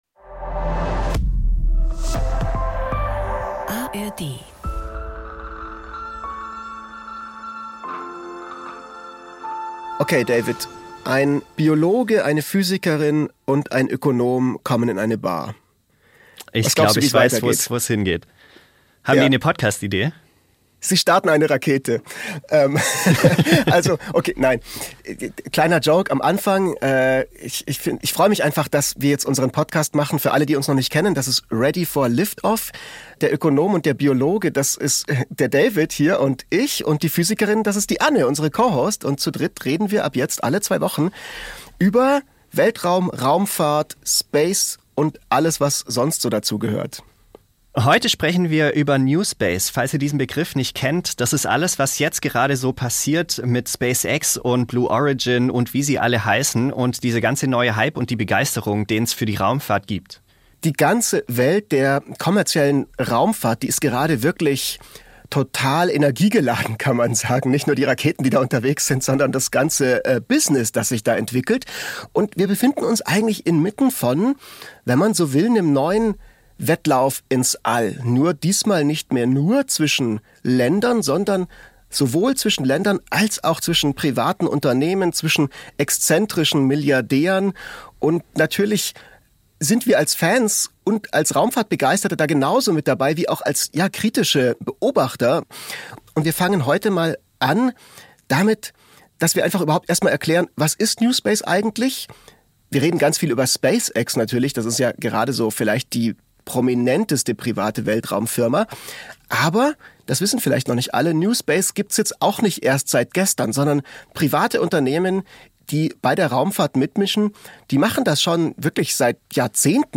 Im Podcast „Ready for Liftoff“ dreht sich alles um diese besonderen Momente der Raumfahrtgeschichte, aber auch um aktuelle Missionen, spannende Zukunftsvisionen und überraschende Einblicke in die Welt der Astronauten und Forscher. Locker erzählt, unterhaltsam und immer verständlich – ohne komplizierte Physik.